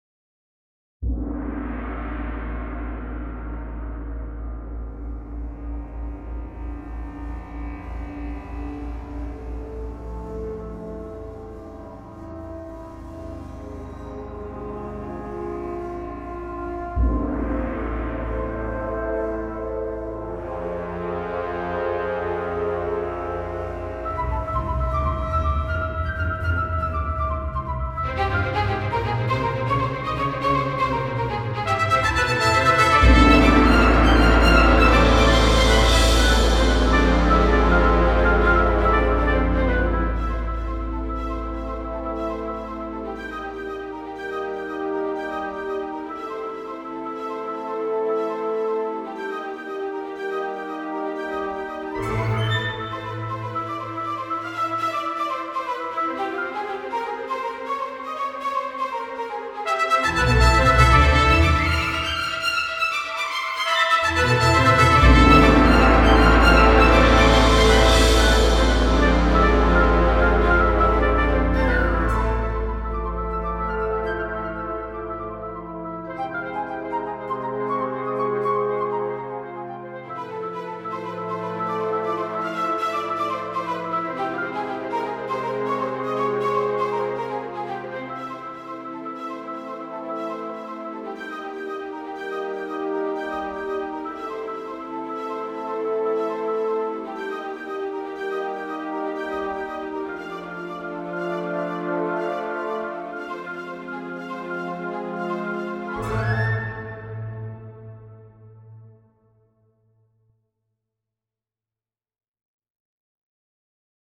fictional soundtrack
Excerpt from a classically orchestrated dramatic score